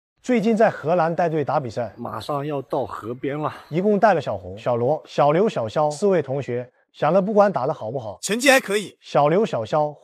电影级体育纪录片AI配音
用深沉、洪亮的AI声音捕捉每一次运动征程中的坚韧、荣耀和情感，专为高风险的体育故事叙述和运动员简介而设计。
电影级旁白
体育纪录片